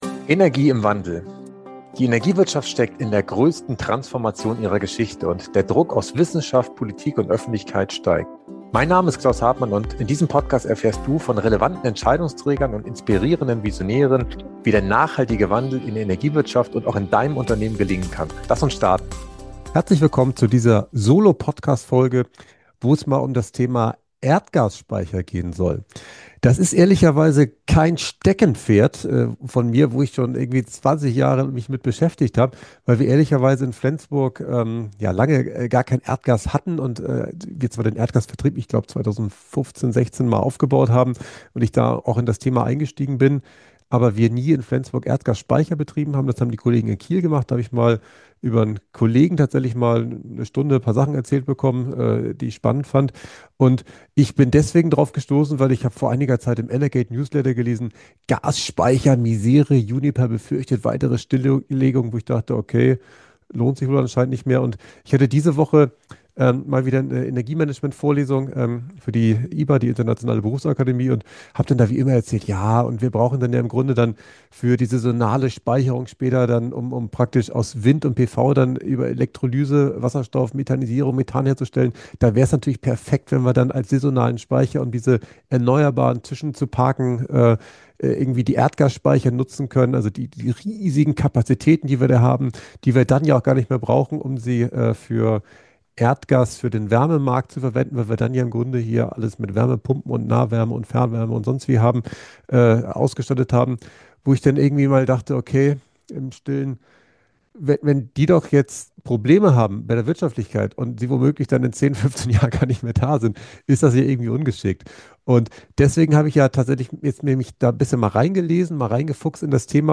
In dieser Solo-Folge von Energie im Wandel werfe ich einen persönlichen Blick auf die aktuelle Situation der Gasspeicher: Warum sind die Füllstände aktuell niedriger als gewohnt?